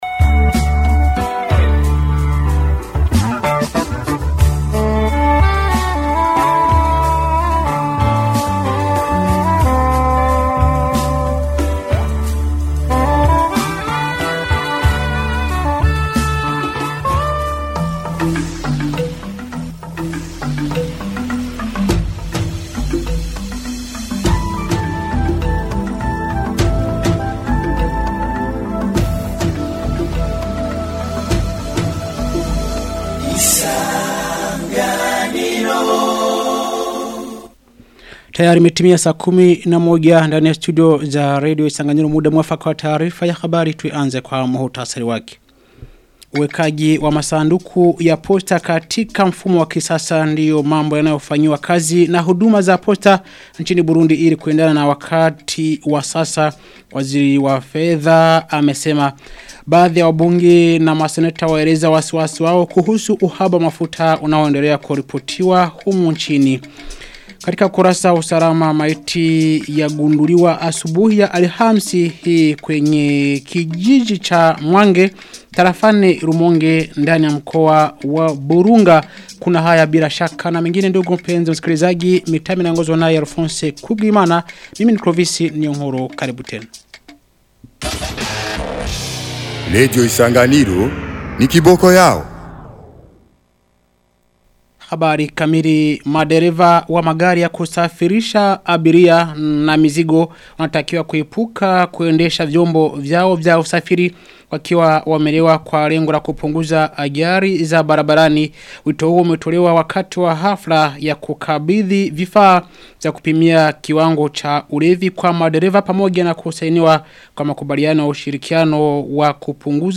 Taarifa ya habari ya tarehe 9 Oktoba 2025